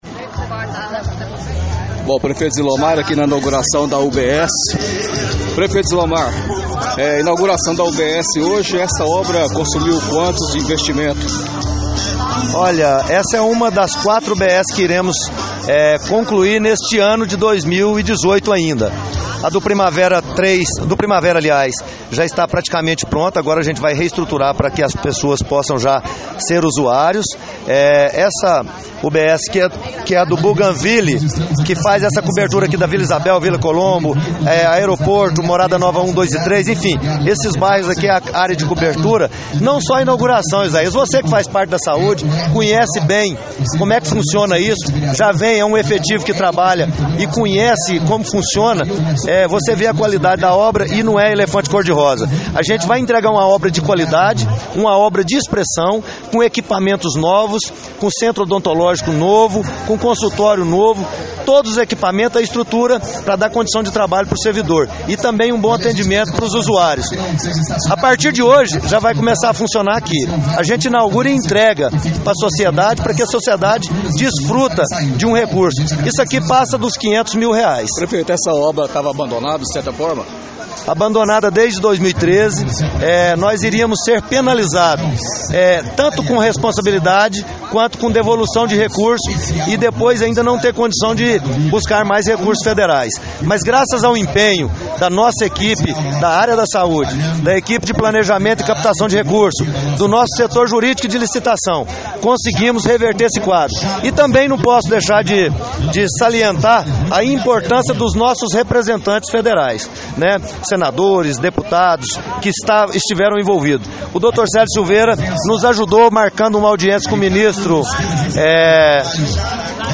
Prefeito Zilomar